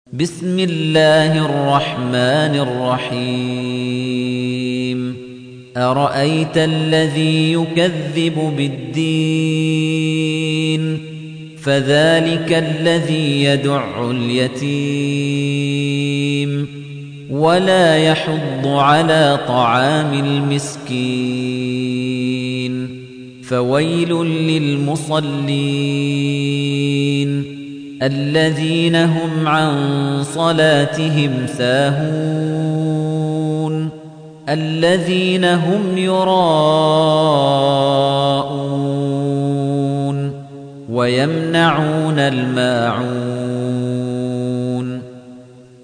تحميل : 107. سورة الماعون / القارئ خليفة الطنيجي / القرآن الكريم / موقع يا حسين